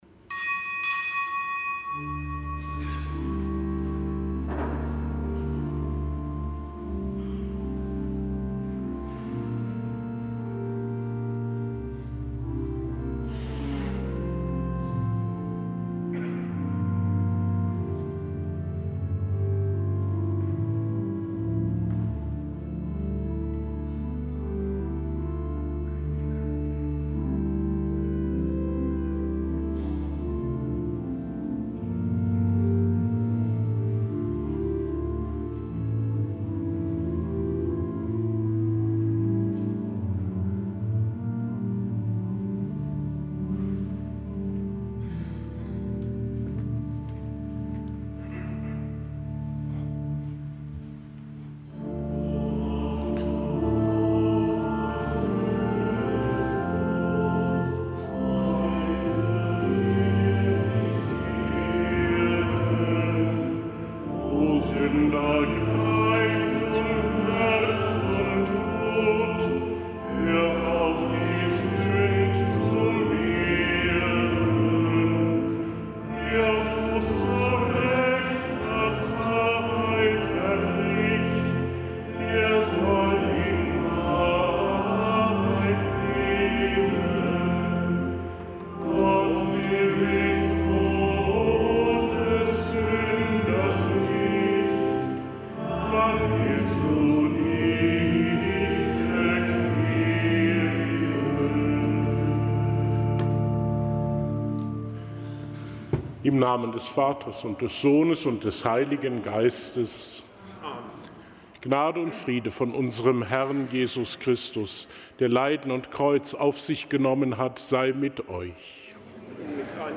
Kapitelsmesse aus dem Kölner Dom am Samstag der fünften Fastenwoche.